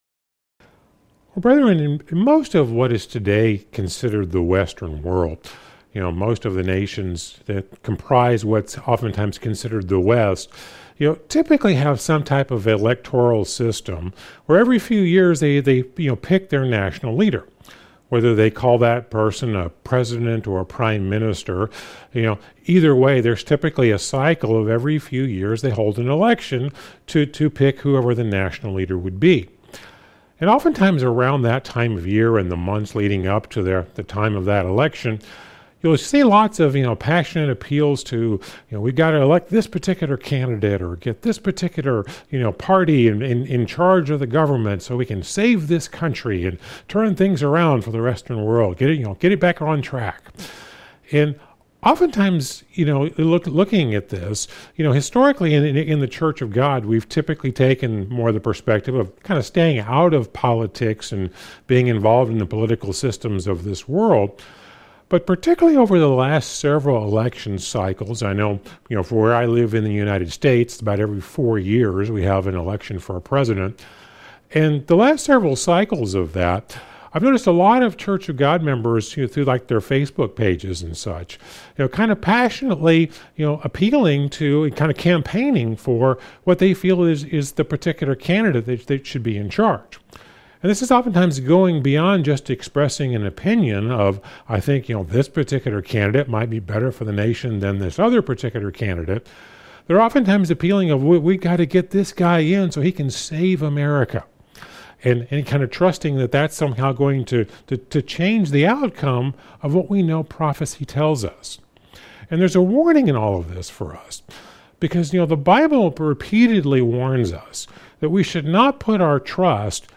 Sermons – Searching The Scriptures